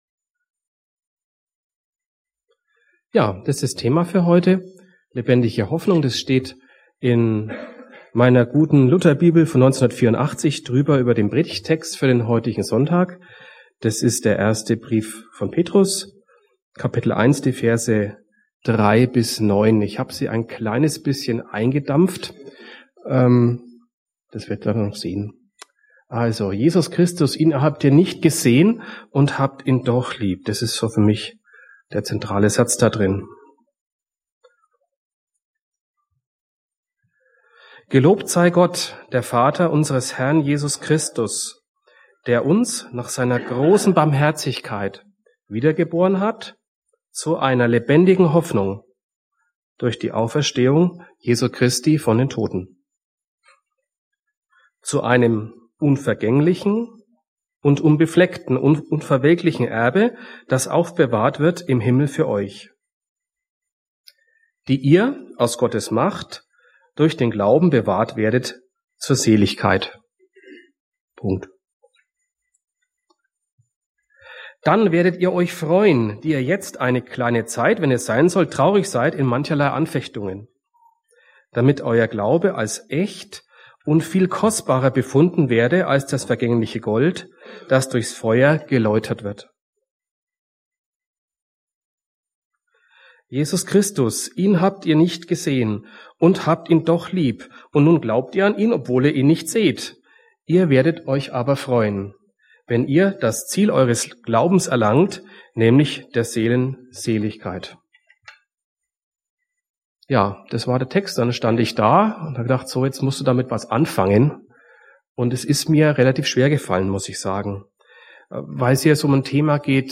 Nach drei Wochen Pause endlich wieder eine Predigt aus der Stadtmission.